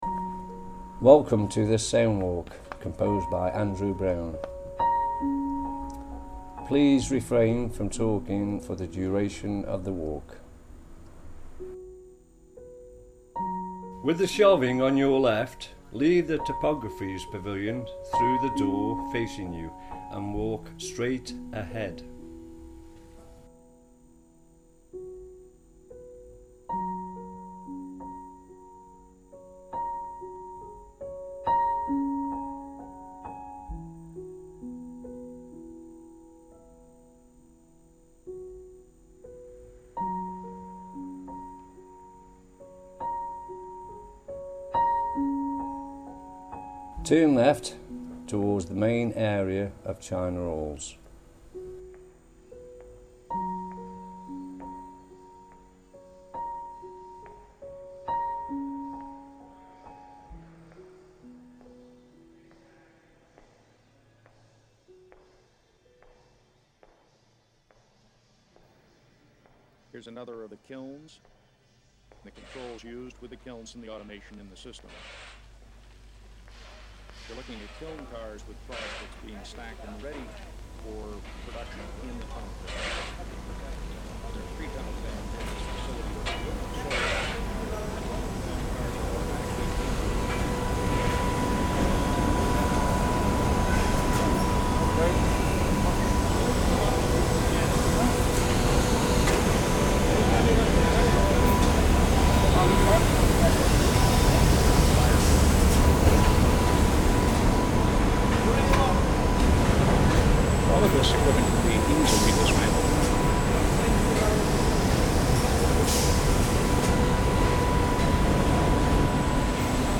'A walk through S' leads participants through areas of the site, accompanied by sounds now absent and/or extinct, and designed to evoke former and present activity on the site.
Please ensure that you use stereo headphones in order to enjoy the full experience.